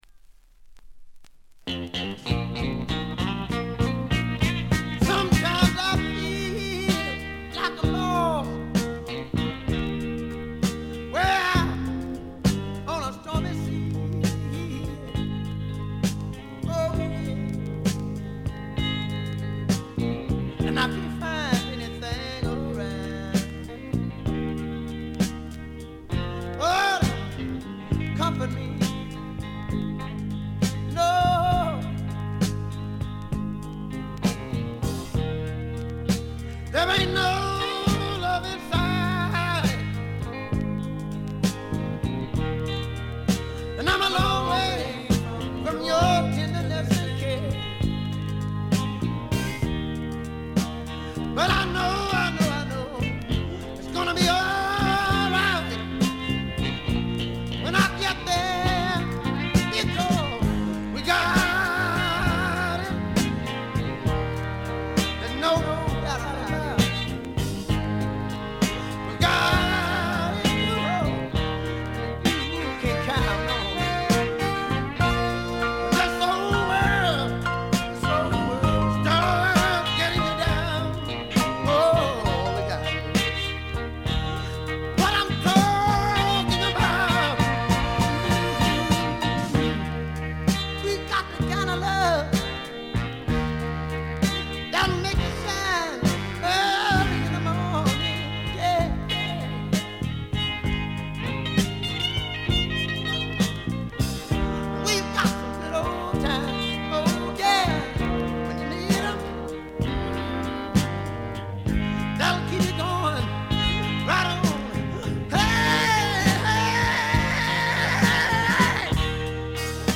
部分試聴ですが、わずかなノイズ感のみ。
予備知識がなければ100人が100人とも黒人シンガーと間違えてしまうだろうヴォーカルが、まず凄い！
試聴曲は現品からの取り込み音源です。